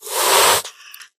hiss1.ogg